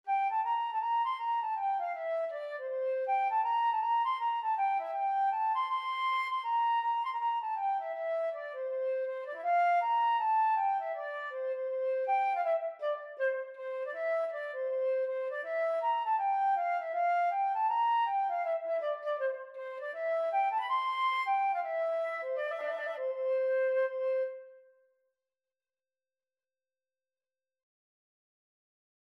4/4 (View more 4/4 Music)
C6-C7
Flute  (View more Easy Flute Music)
Traditional (View more Traditional Flute Music)